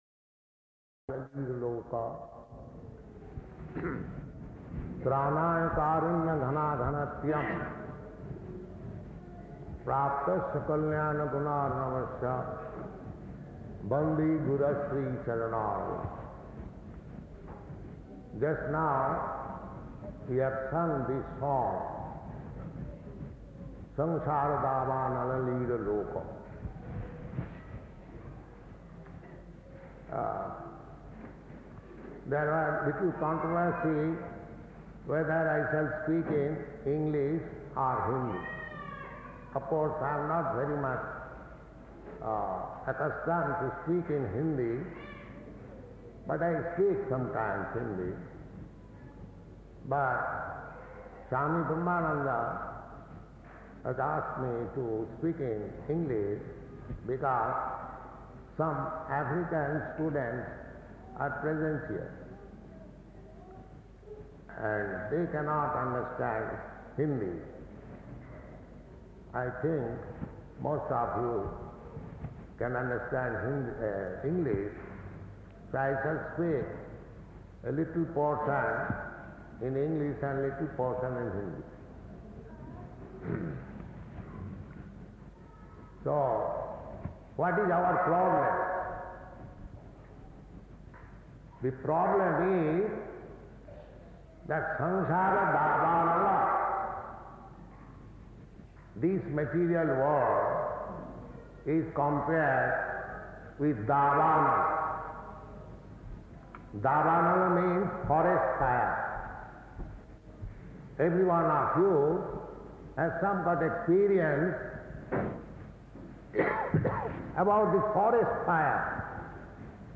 Type: Lectures and Addresses
Location: Nairobi